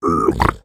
Minecraft Version Minecraft Version 1.21.4 Latest Release | Latest Snapshot 1.21.4 / assets / minecraft / sounds / mob / piglin / admire2.ogg Compare With Compare With Latest Release | Latest Snapshot
admire2.ogg